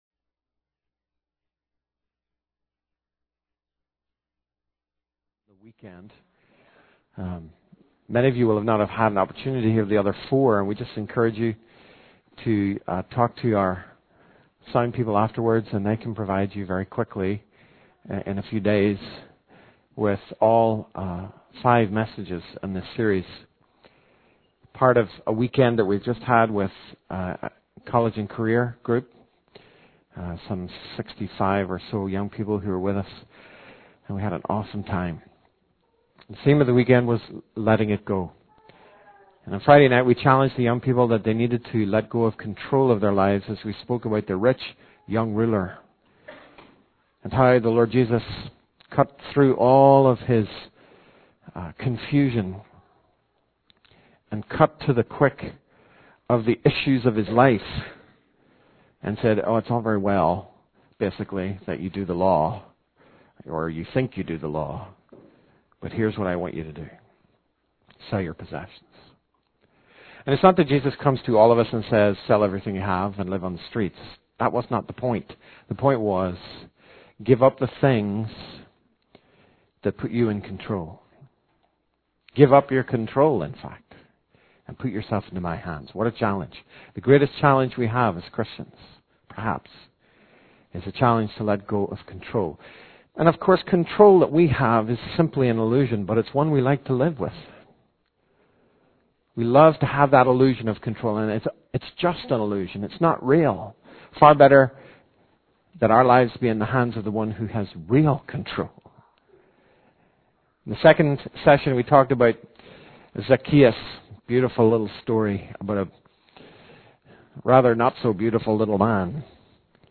In this sermon, the speaker discusses the theme of 'letting go' and challenges young people to let go of control in their lives. He uses the story of the rich young ruler to illustrate the point that we need to give up the things that put us in control. The speaker then moves on to discuss the story of Jesus calling Peter to follow him and how Peter had to let go of his fear and doubts.